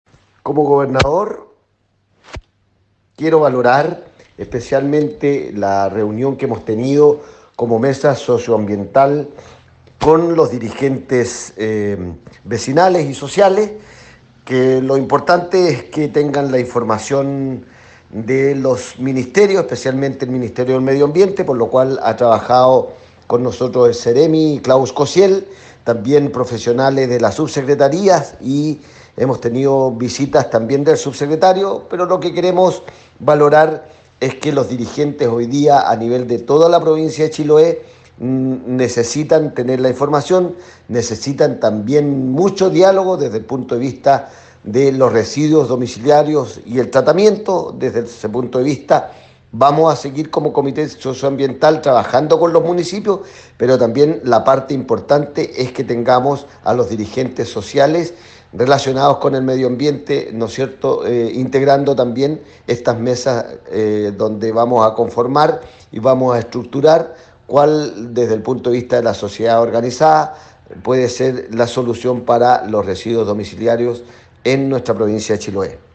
En ese sentido, el Gobernador Provincial de Chiloé, Fernando Bórquez, indicó que se continuará como Comité Socioambiental trabajando con los municipios y dirigentes sociales.